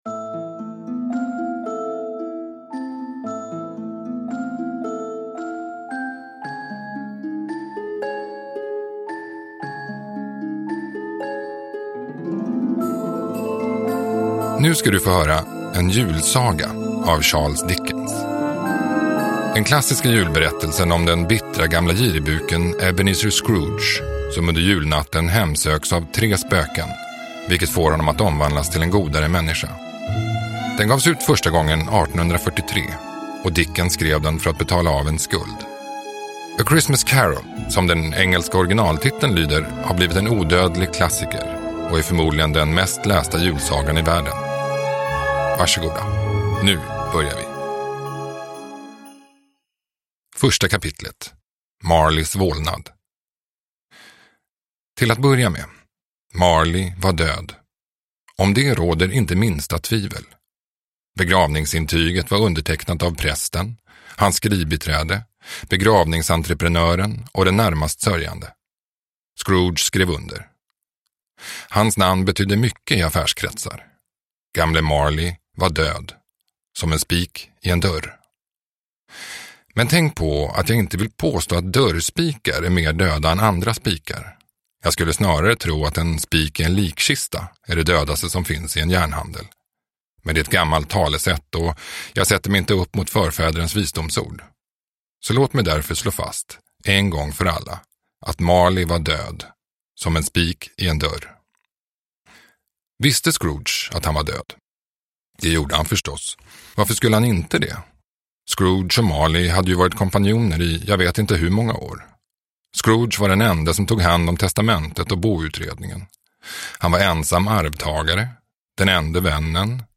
En julsaga – Ljudbok